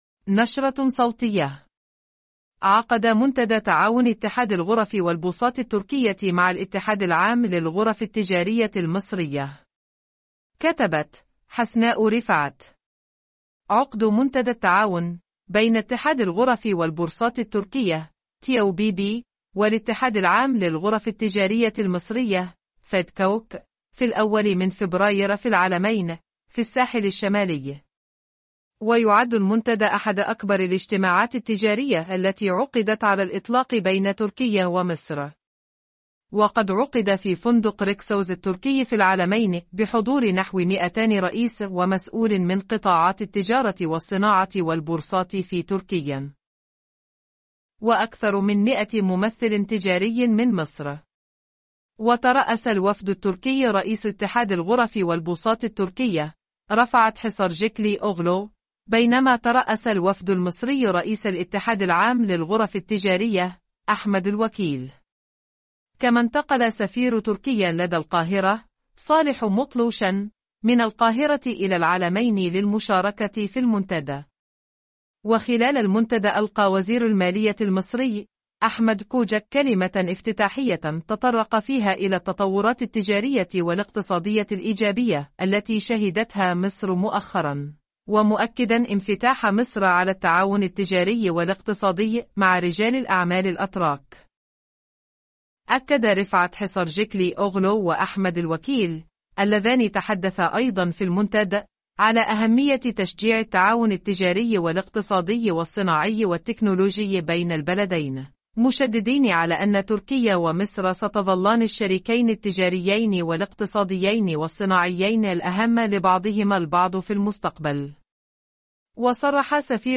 نشرة صوتية..